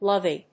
音節lov・ey 発音記号・読み方
/lˈʌvi(米国英語)/